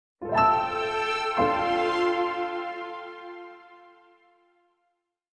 Музыкальный эффект Piano № 2